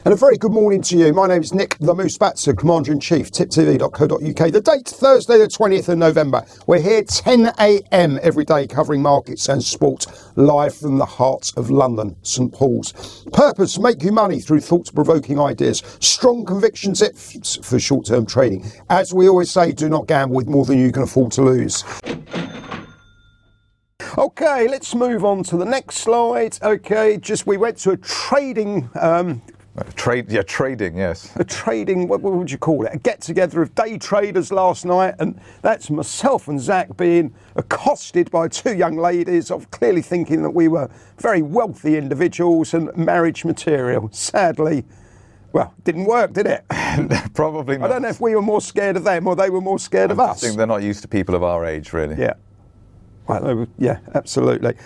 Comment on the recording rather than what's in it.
Live Market Round Up & Soapbox Thoughts